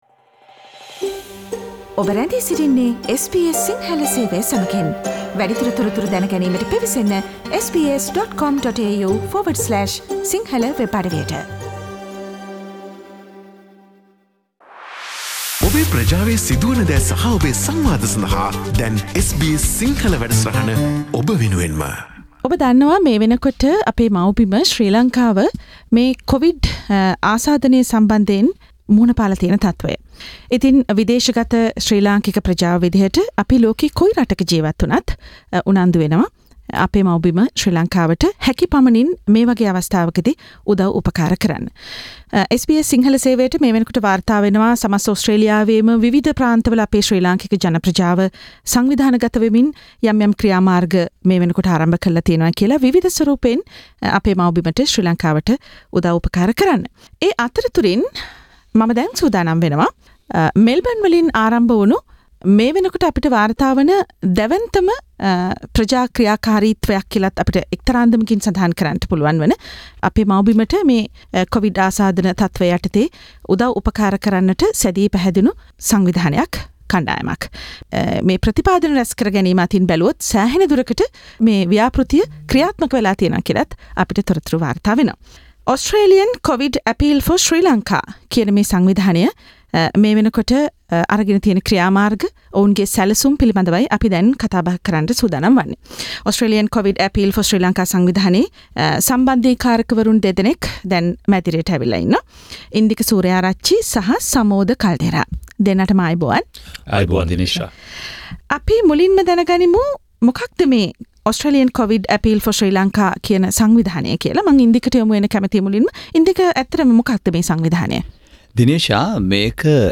@ SBS studios